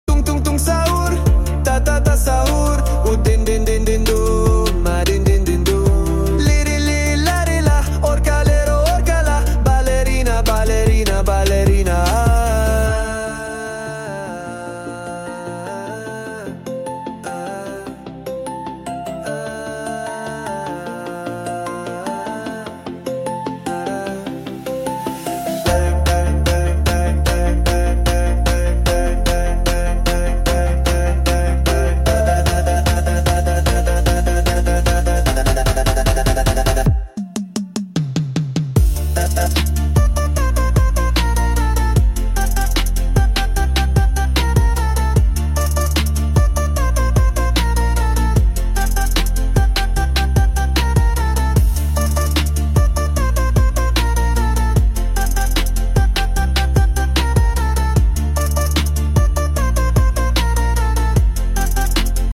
Brainrot RAP!🎶 BIG EGG! BABIES sound effects free download